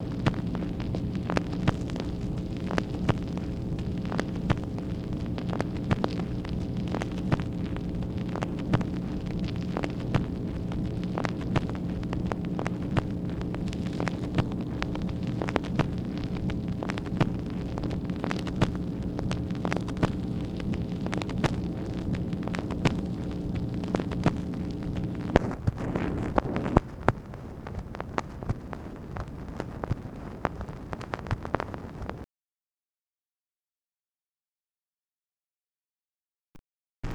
MACHINE NOISE, August 21, 1964
Secret White House Tapes | Lyndon B. Johnson Presidency